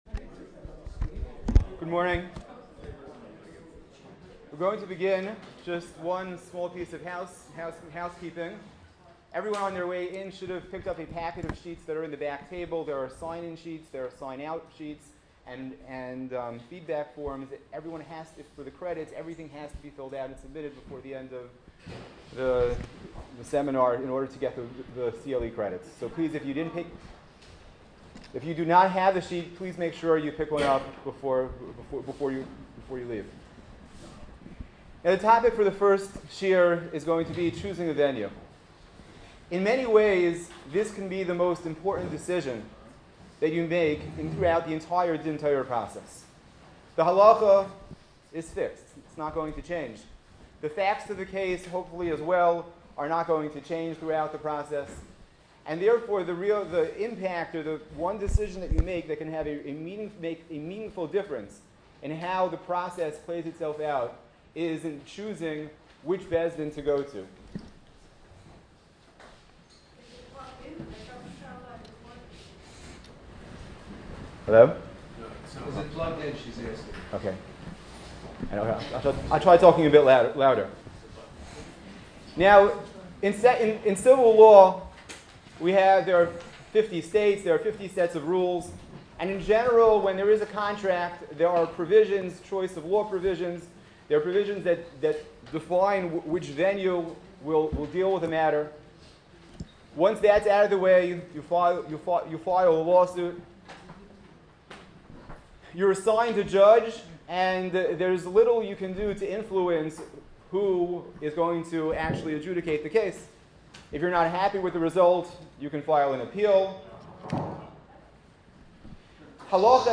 CLE symposium June 14, 2015